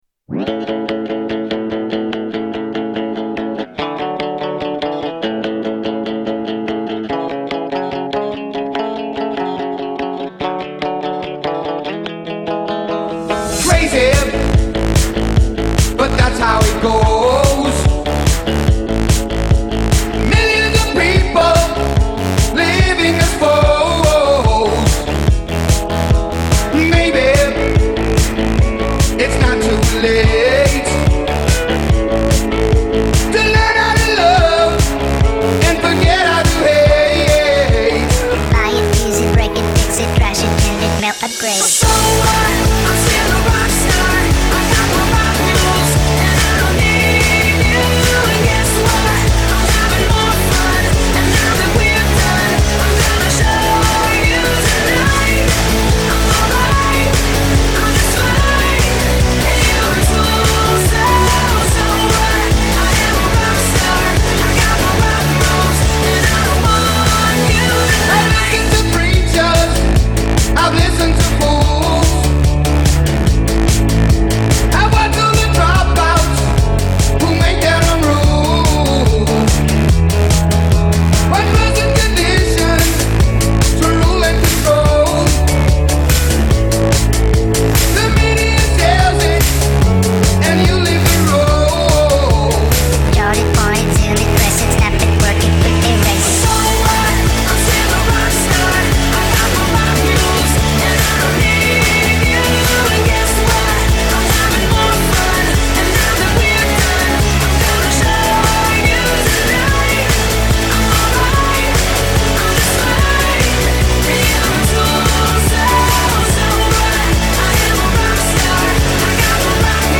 Mash Up songs